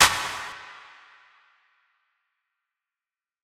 Adaptation Clap.wav